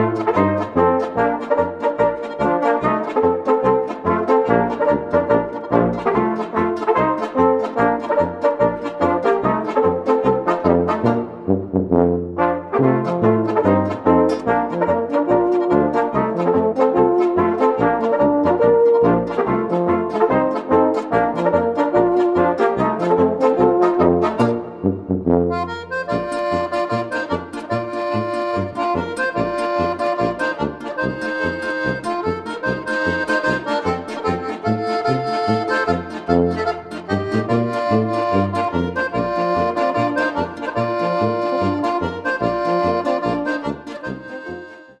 Gattung: Volksmusik natürlicher und ursprünglicher Art
Besetzung: Volksmusik/Volkstümlich Weisenbläser
Akkordeon in C
Flügelhorn in B